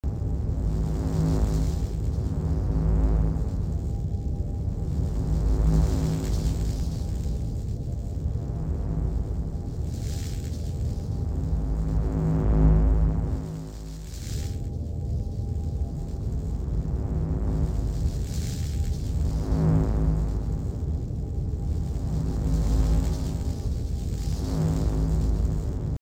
reactor.mp3